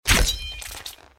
bt_slash_simple1.mp3